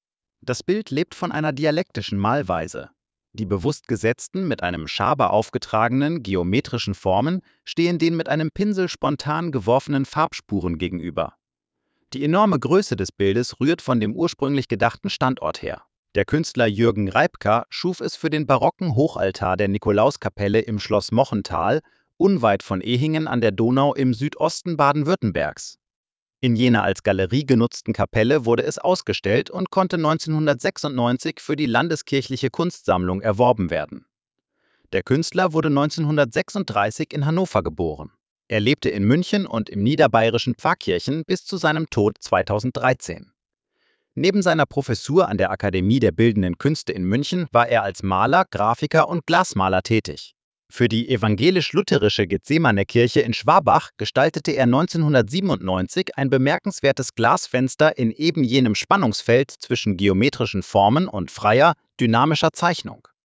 Audiostimme: KI generiert